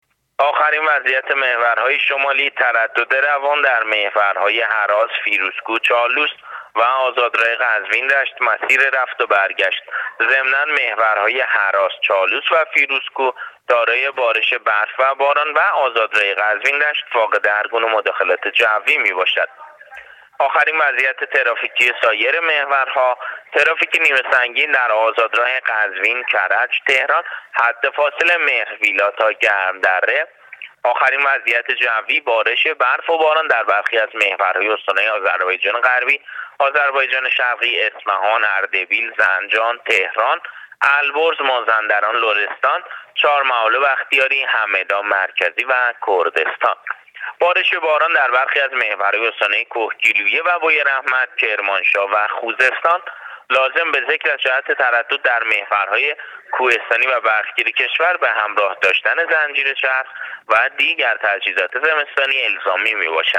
گزارش رادیو اینترنتی از آخرین وضعیت ترافیکی جاده‌ها تا ساعت ۹ پنج‌شنبه یکم اسفند ۱۳۹۸